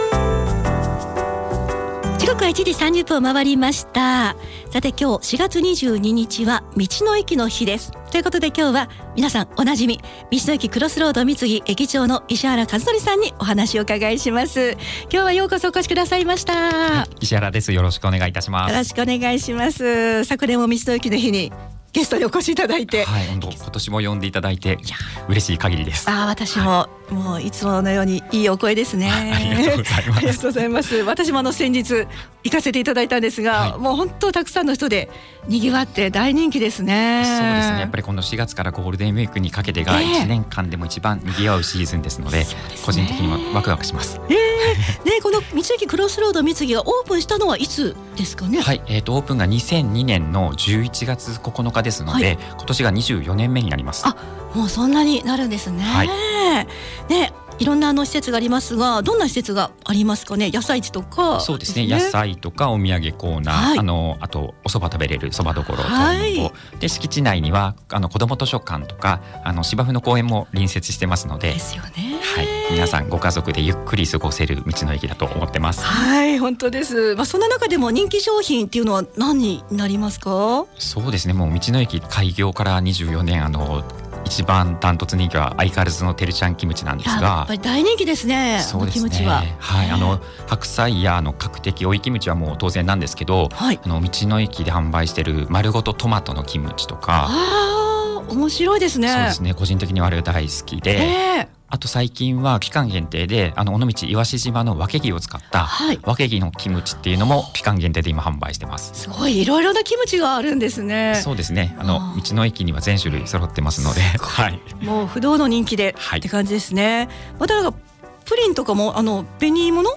クロスロードみつぎの施設や人気商品、初の取組みとなる御調産サツマイモをつかった焼酎づくりなどについてお聞きしました。